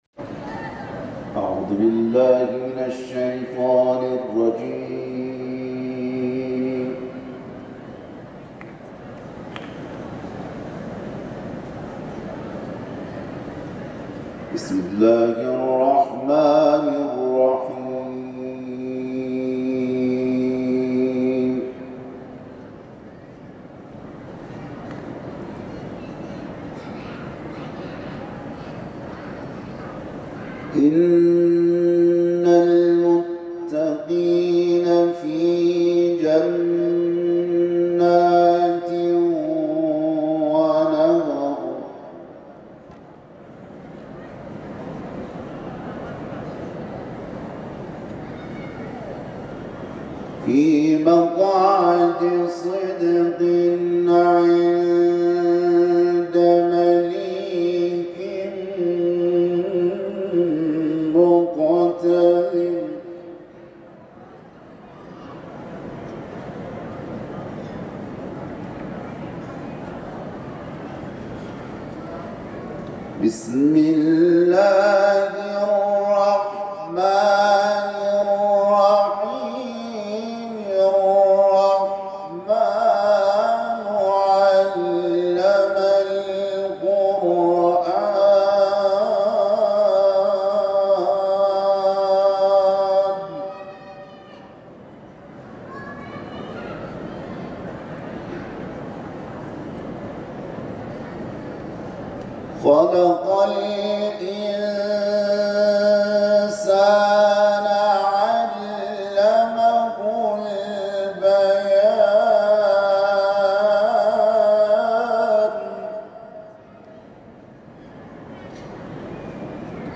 این قاری بین‌المللی به تلاوت آیات 54 و 55 سوره مبارکه قمر و آیه اول تا 21 سوره مبارکه الرحمن پرداخته است.
قاری بین المللی